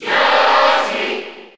Category:Crowd cheers (SSB4) You cannot overwrite this file.
Yoshi_Cheer_Spanish_PAL_SSB4.ogg